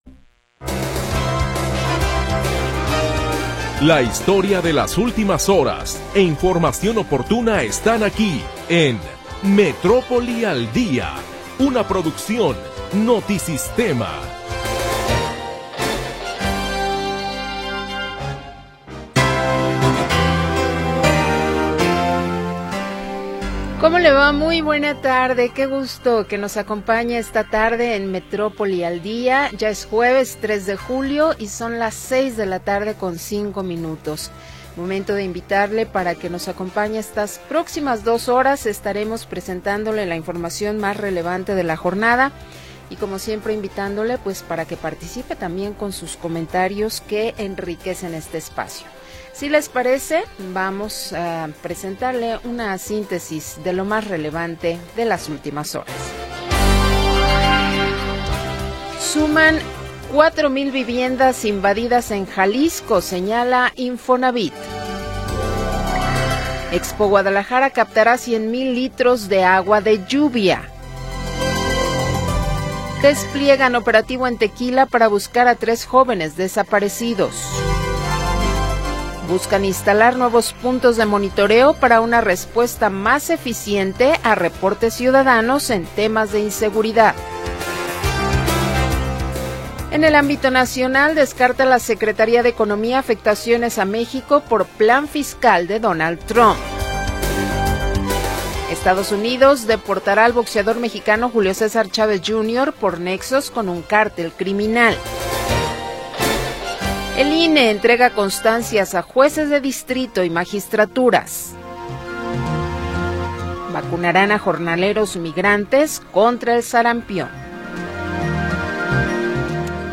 Primera hora del programa transmitido el 3 de Julio de 2025.